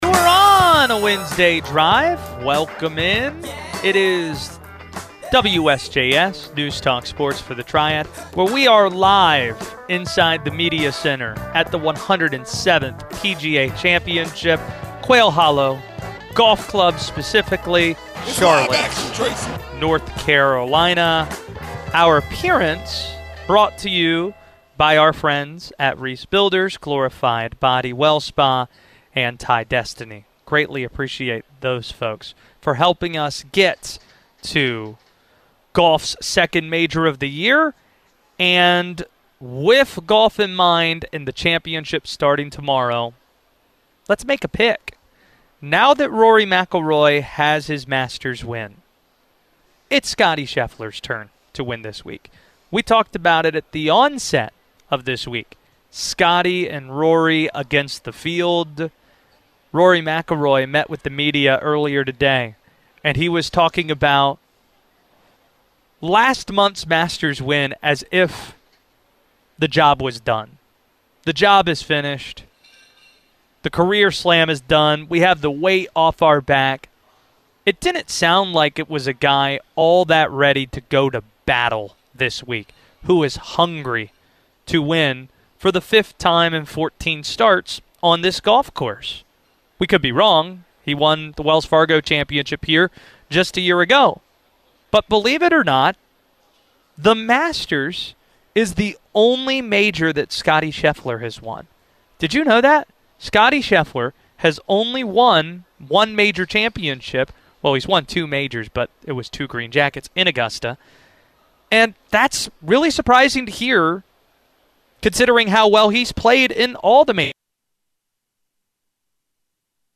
live from the PGA Championship at Quail Hollow